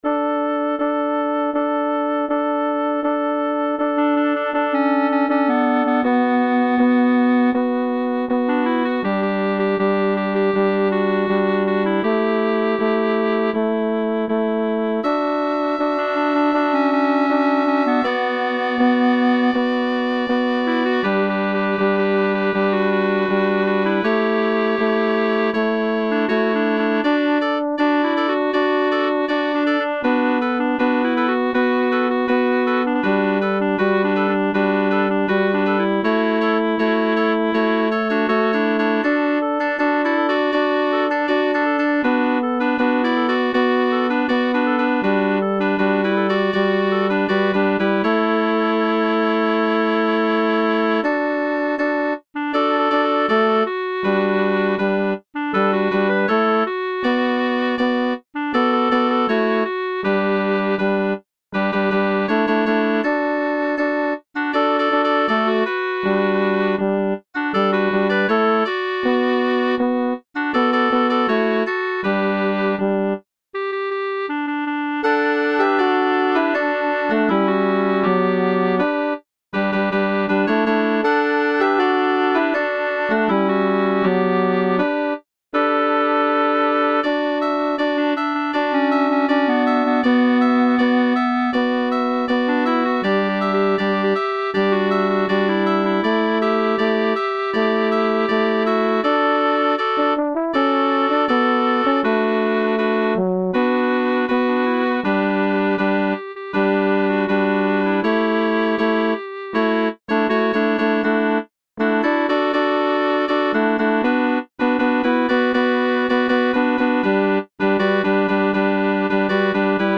Learning Track Sample